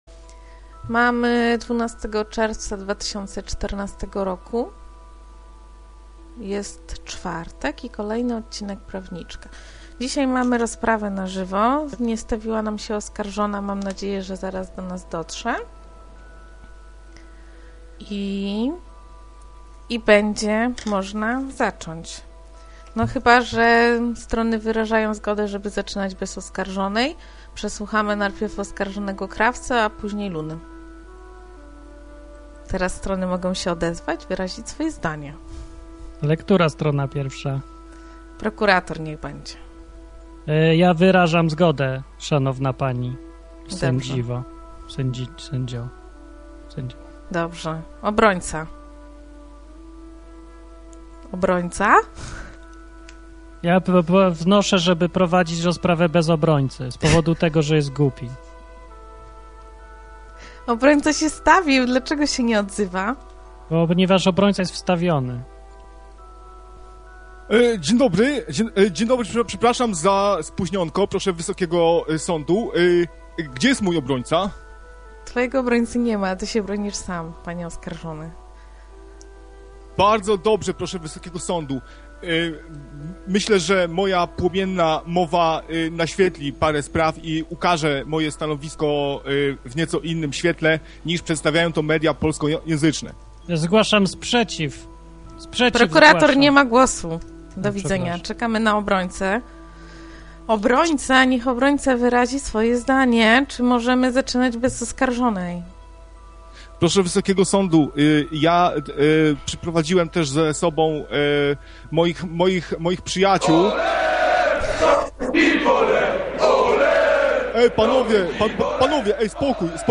Rozprawa na żywo